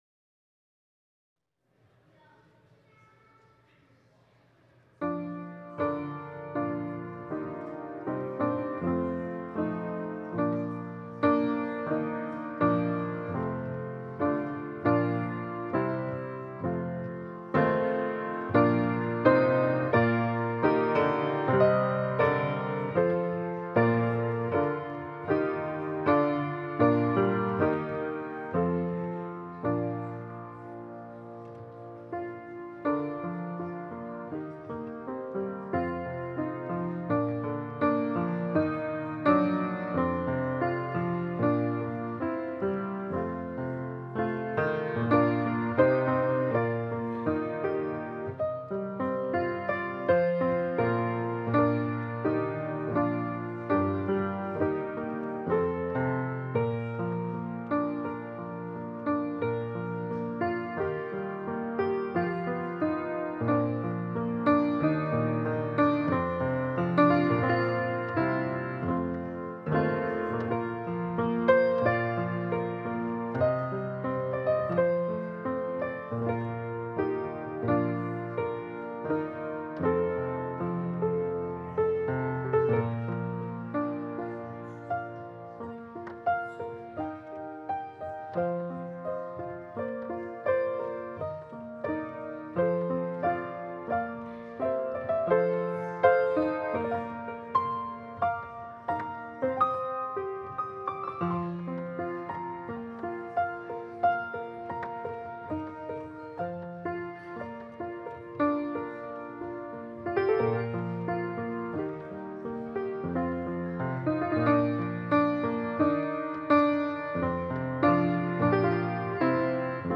Worship service at St. John’s Presbyterian Church in Cornwall, Ontario, Canada.